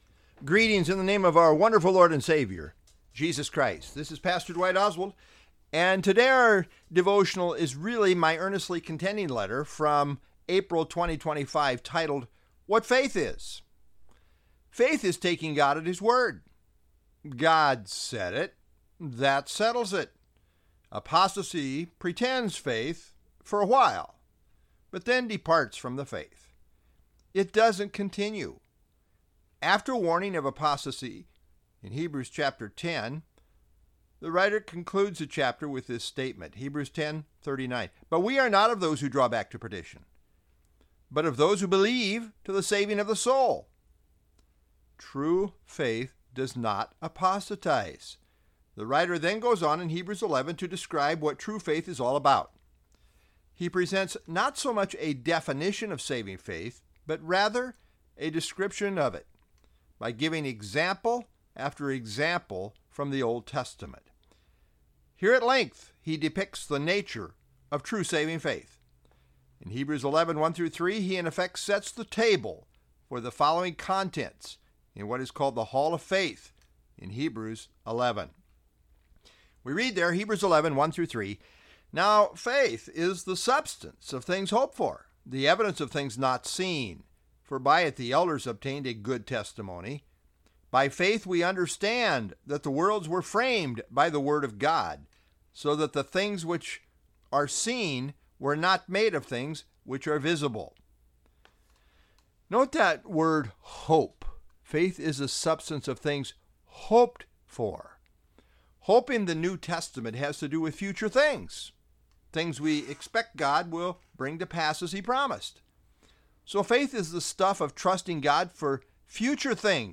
Sermon Series Earnestly Contending | Southview Bible Church